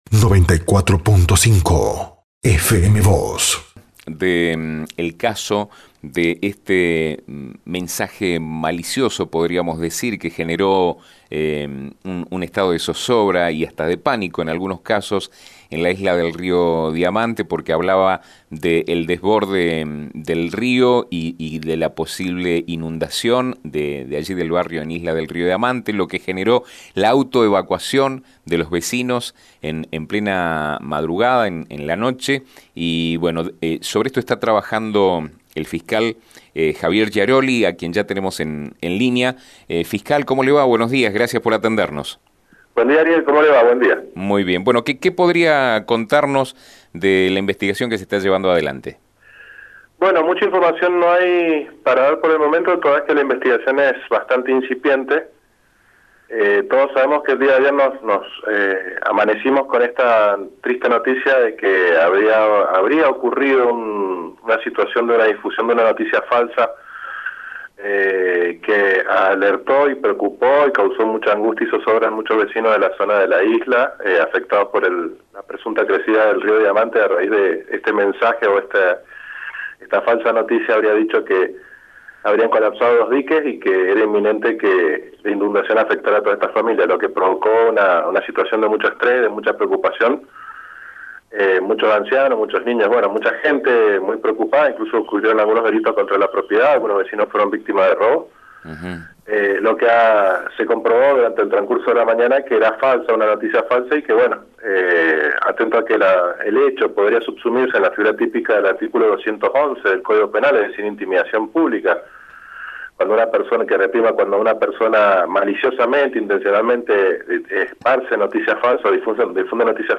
“La investigación es incipiente, ayer (por el lunes) amanecimos con la triste noticia de la difusión de la noticia falsa que habría ocurrido y que causó mucha zozobra en muchos vecinos de la Isla, que decía que habían colapsado dos diques y era inminente la inundación, lo que provocó que salieran incluso ancianos y niños”, dijo Giaroli a FM Vos (94.5) y Diario San Rafael.